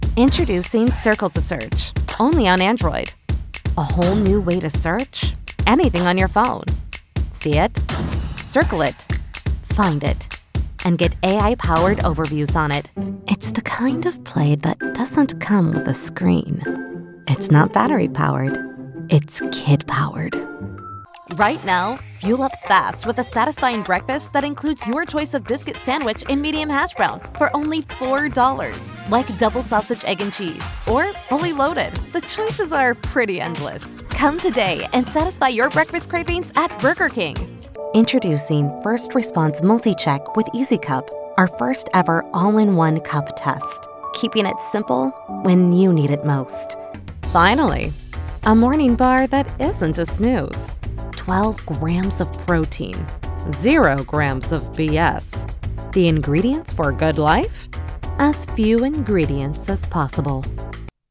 Chaleureux
Naturel
De la conversation